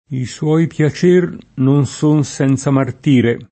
i SU0i pLa©%r non S1n SHnZa mart&re] (Poliziano)